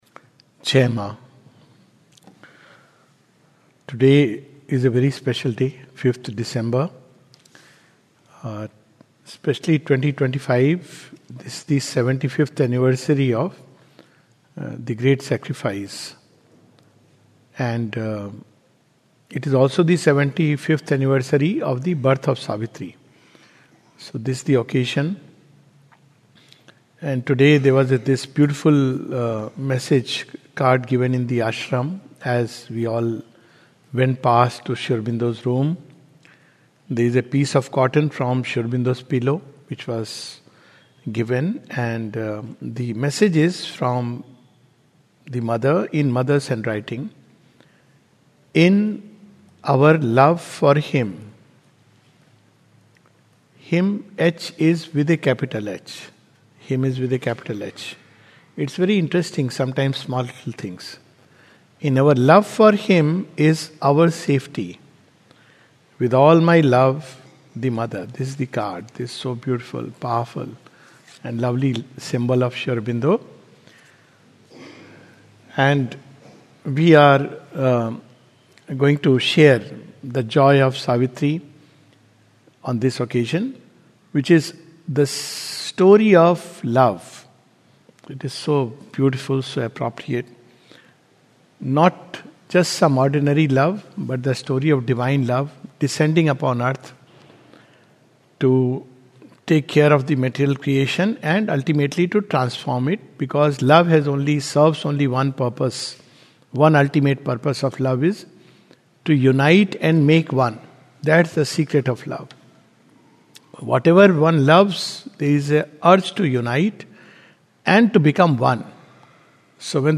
at Savitri Bhavan on the occasion of the 75th Anniversary of Savitri.